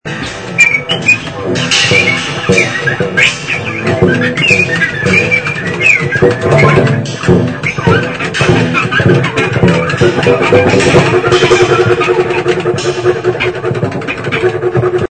improvisations